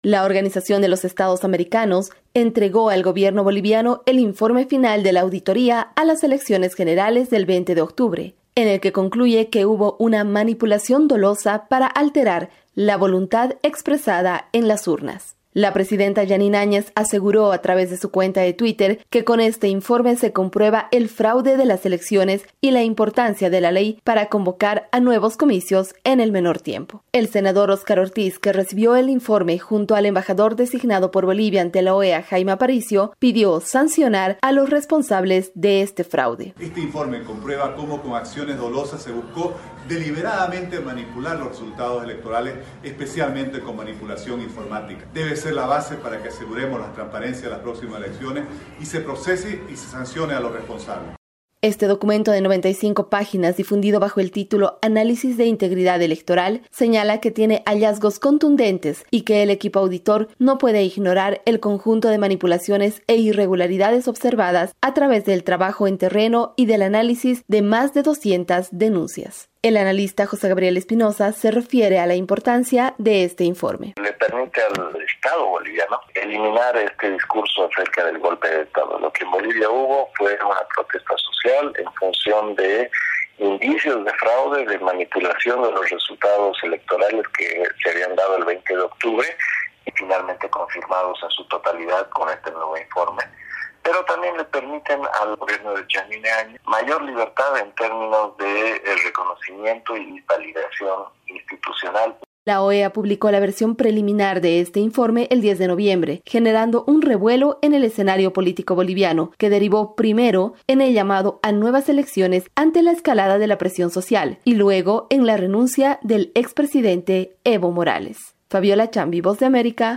VOA: Informe de Bolivia